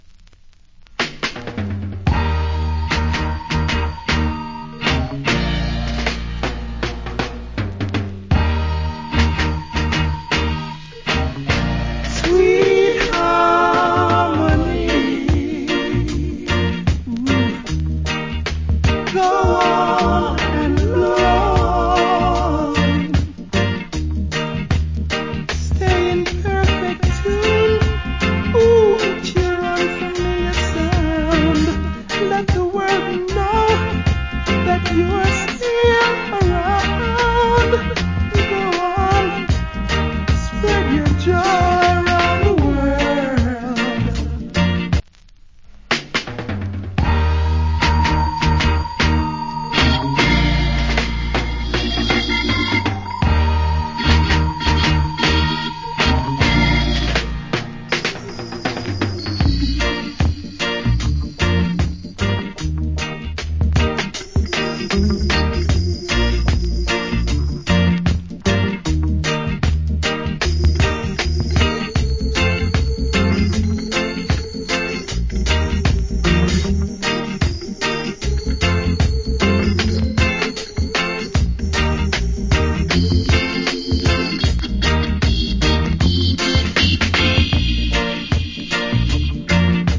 Sweet Reggae Vocal.